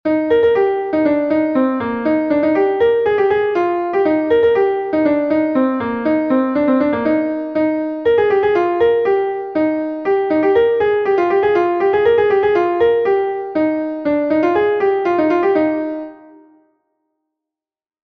Gavotenn Bro-Bourled is a Gavotte from Brittany